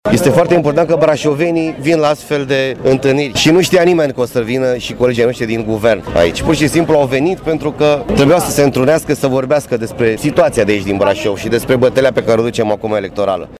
UPDATE: Miting PSD în Piața Sfatului din Brașov
Codrin Ştefănescu, secretar general al PSD: